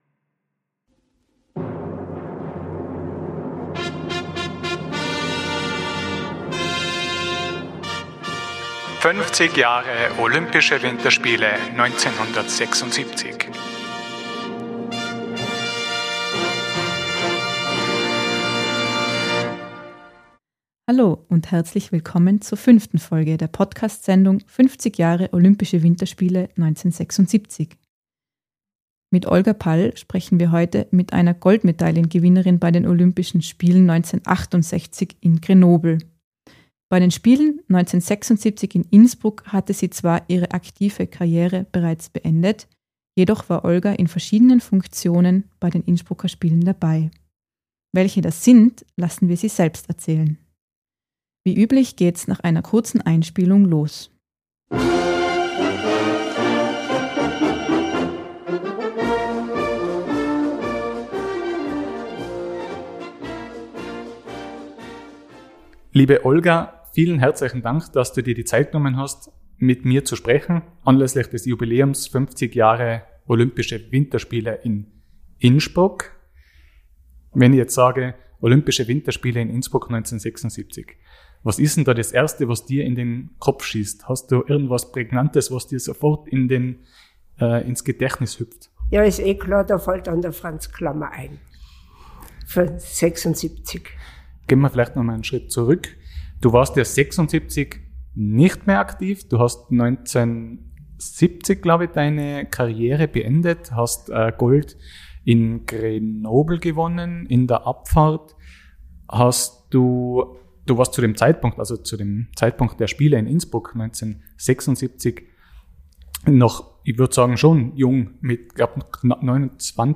Mit Olga Pall sprechen wir heute mit einer Goldmedaillengewinnerin bei den Olympischen Spielen 1968 in Grenoble. Bei den Spielen 1976 in Innsbruck hatte sie jedoch ihre aktive Karriere bereits beendet.